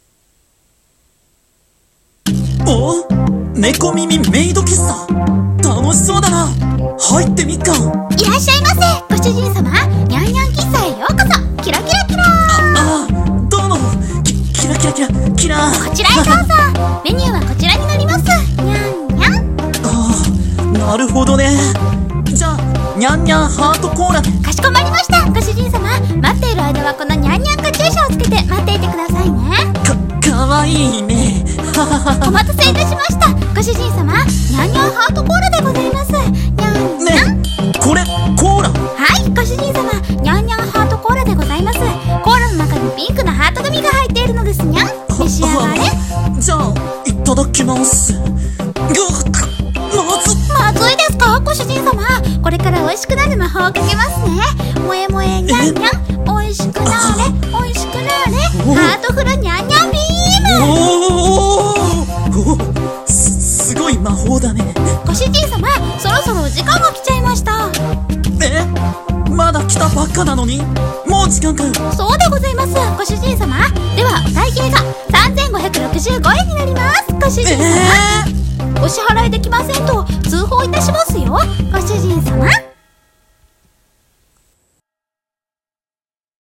【猫耳メイド喫茶へようこそ！ご主人様♡】【2人声劇】【コラボ募集】